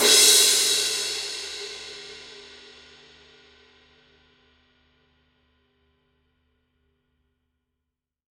Son : Clair, traditionnel, aéré, défini avec une définition tonale chaleureuse et claire
Volume: faible à moyen
Son avec la baguette: diffus
Intensité: rapide, vive
Sustain: moyen
Caractère de la cloche: intégré au halo
Caractère du son: Sombre, transparente, chaude. Spectre de fréquences assez large, mix très complexe. Très sensible, réponse souple. Les plus grands diamètres conviennent à un jeu léger.
signature_traditionals_18_thin_crash_edge.mp3